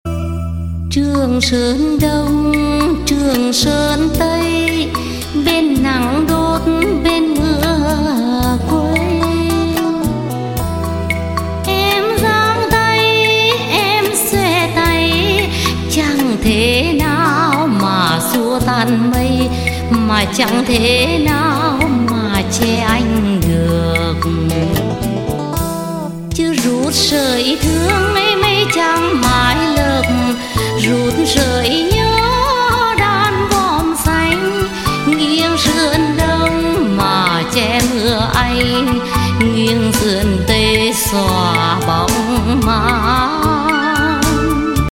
Thể loại nhạc chuông: Nhạc trữ tình